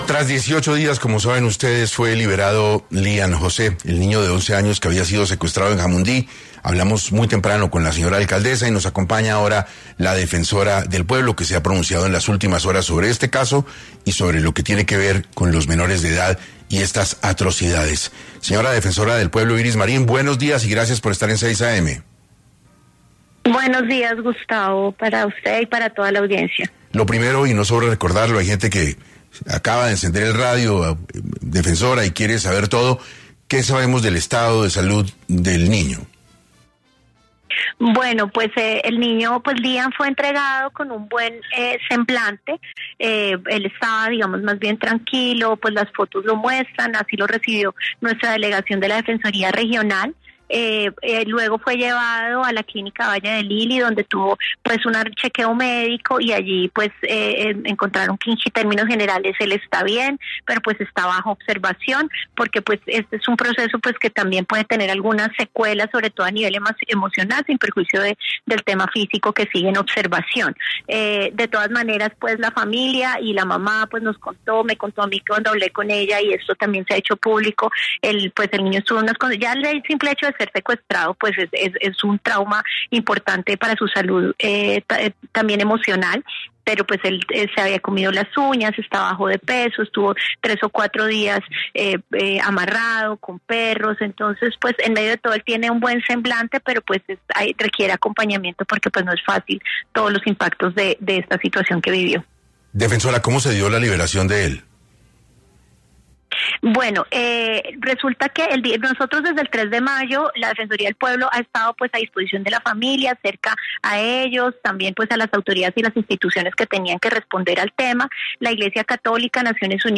En este orden de ideas, Iris Marín, defensora del Pueblo, estuvo tras los micrófonos de 6AM para dar más detalles de la liberación del menor.